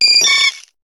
Cri de Papilusion dans Pokémon HOME.